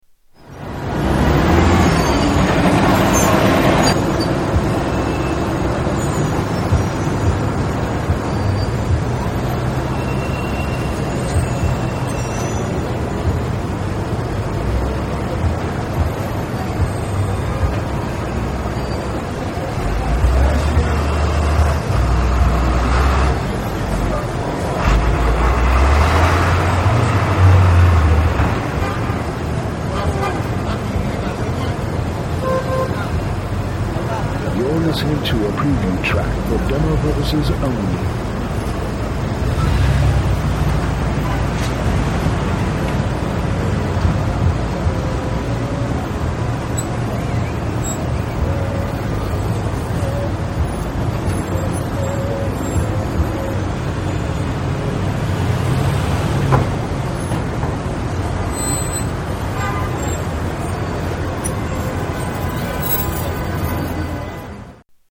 Traffic at Times Square
Tags: New York New York city New York city sounds NYC Travel